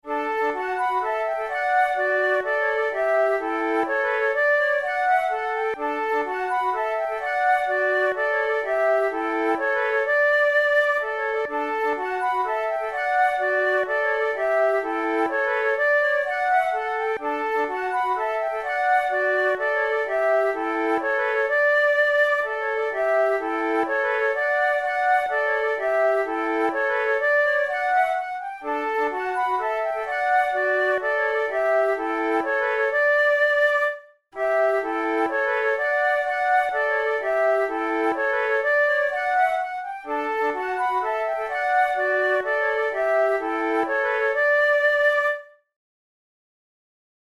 InstrumentationFlute trio
KeyD major
Time signature3/4
Tempo126 BPM
Waltzes
for three flutes
the-union-waltz-trio.mp3